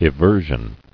[e·ver·sion]